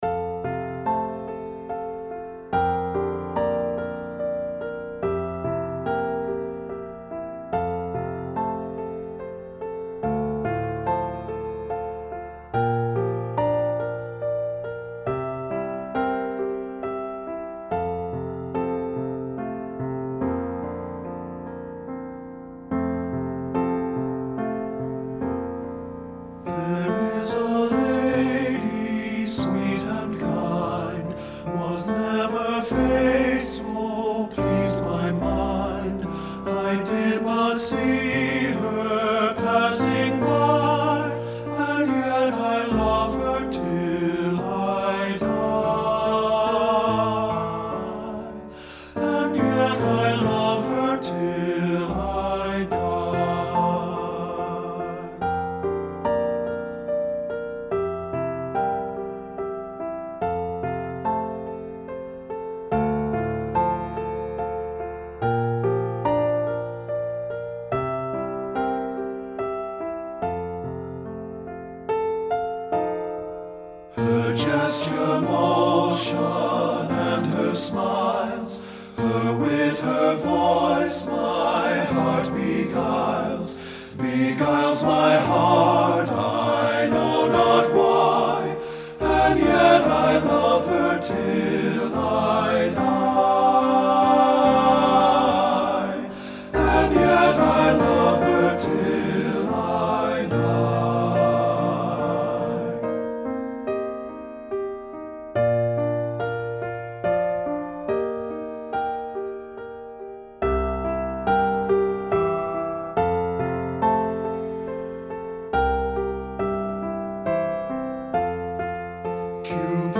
Voicing: TB and Piano